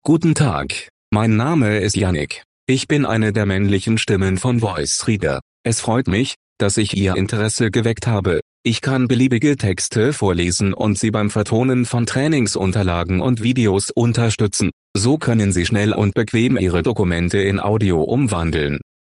Voice Reader Home 22 Deutsch - Männliche Stimme [Yannick] / German - Male voice [Yannick]
Voice Reader Home 22 ist die Sprachausgabe, mit verbesserten, verblüffend natürlich klingenden Stimmen für private Anwender.